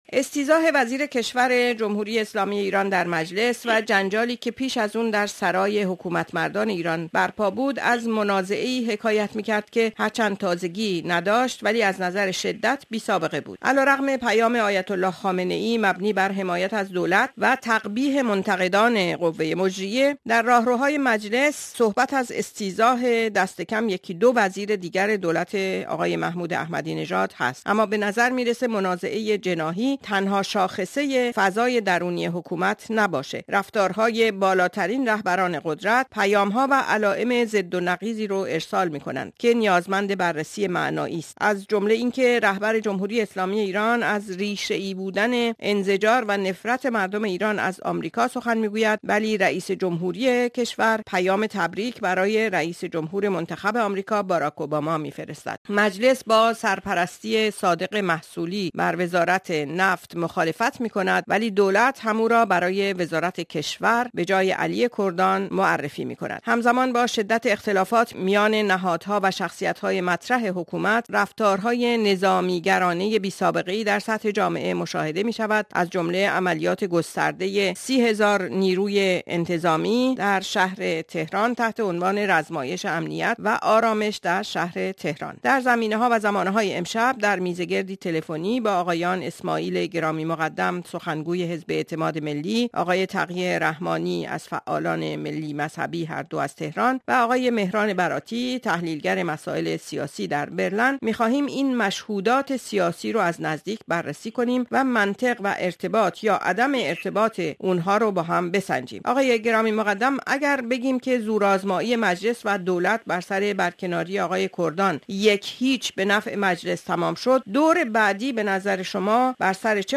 میزگرد رادیوئی را بشنوید